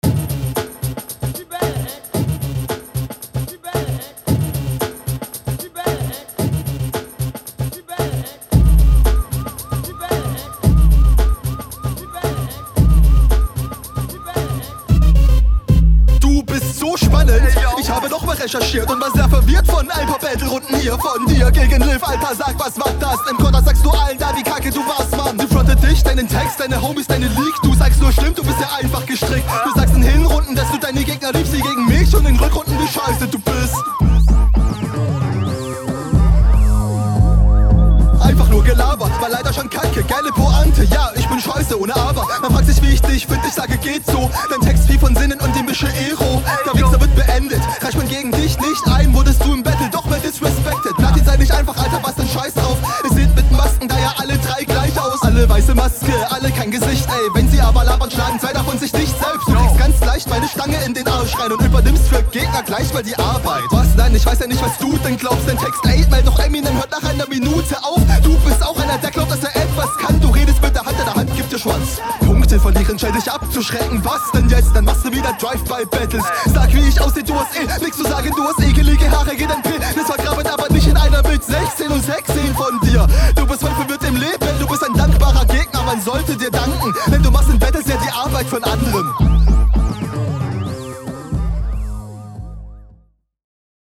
Der Beat ist ABSOLUT ASS.